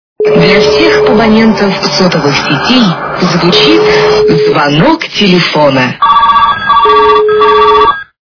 Женский мелодичный голос - Для всех абонентов сотовых телефонов, звучит звонок телефона Звук Звуки Жіночий мелодійний голос - Для всех абонентов сотовых телефонов, звучит звонок телефона
При прослушивании Женский мелодичный голос - Для всех абонентов сотовых телефонов, звучит звонок телефона качество понижено и присутствуют гудки.